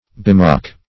Bemock \Be*mock"\, v. t.